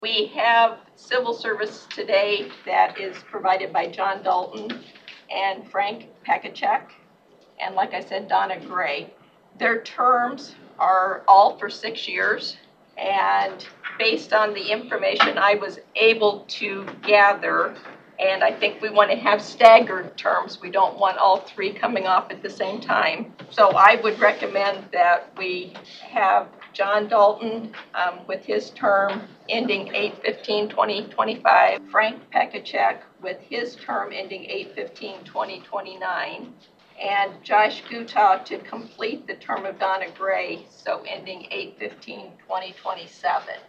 Supervisor Susan Miller provided a little background on who is on the Commission…